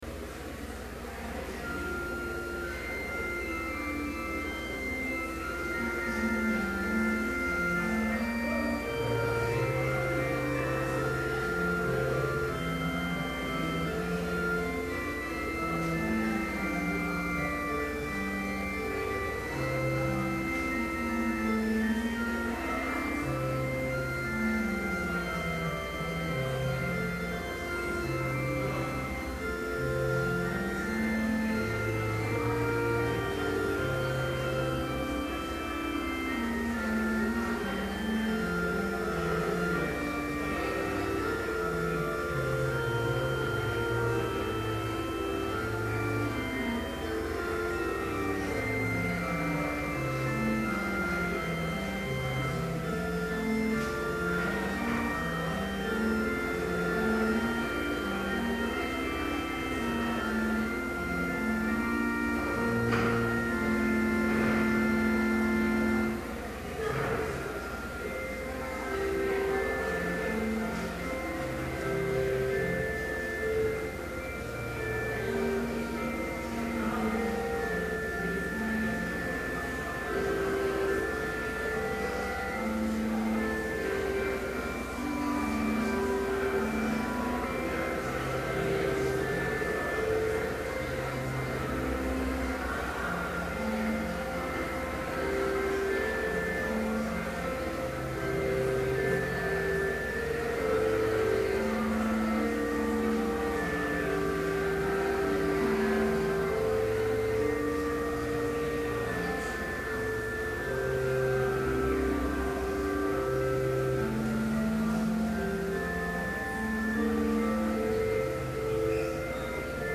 Complete service audio for Chapel - January 11, 2012